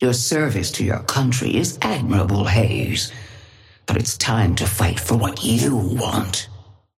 Sapphire Flame voice line - Your service to your country is admirable, Haze, but it's time to fight for what you want.
Patron_female_ally_haze_start_06.mp3